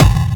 Kick_06.wav